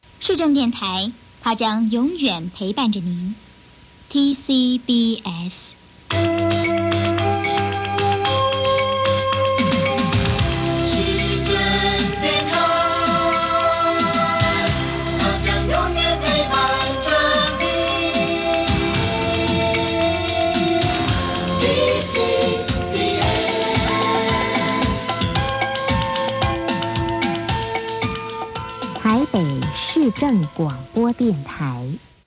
【[現地録音] 台北市政広播電台 93.1MHz 1990/--/-- (中華民国 [Republic of China]) 】
受信時間は0時以降の深夜帯です。
IDは「市政電台 他將永遠陪伴著你 TCBS」ですが、これがそのままSJになっています。